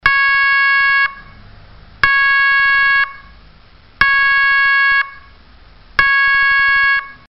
Serie: SIRENAS ELECTRÓNICAS
Elevada potencia acústica - 115dB